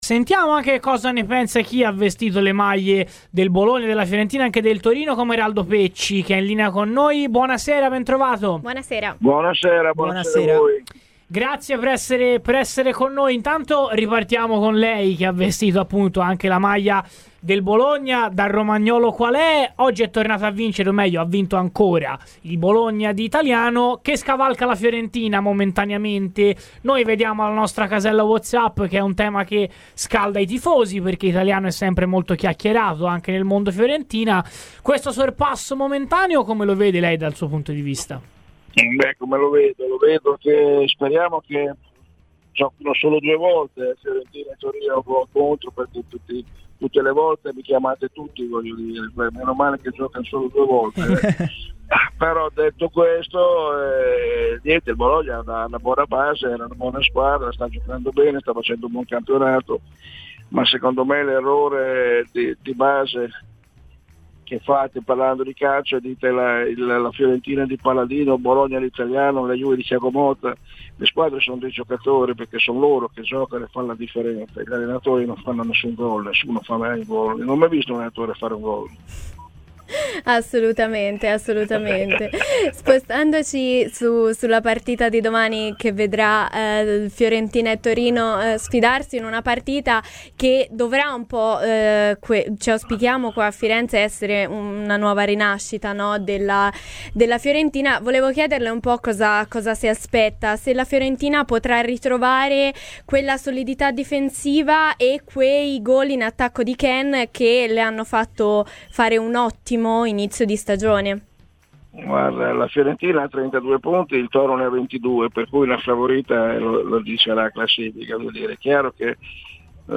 Intervenuto a Radio FirenzeViola nel corso della puntata odierna di Viola Weekend, il doppio ex di Fiorentina e Torino Eraldo Pecci ha detto la sua sulla sfida di domani che vede contrapposti viola e granata: "La Fiorentina ha 32 punti, il Torino ha 22, per cui la favorita la dice la classifica.